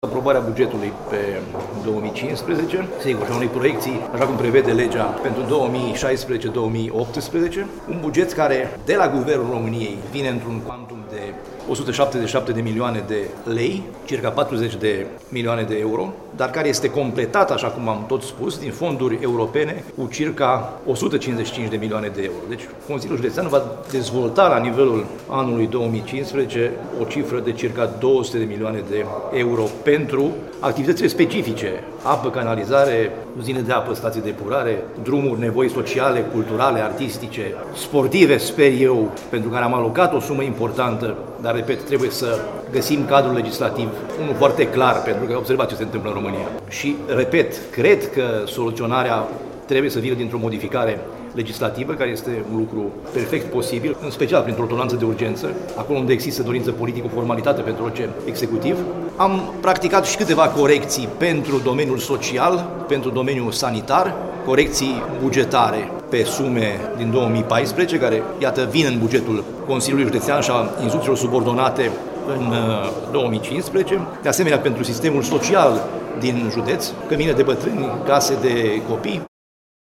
Ascultaţi declaraţia preşedintelui Consiliului Judeţean Caraş-Severin, Sorin Frunzăverde: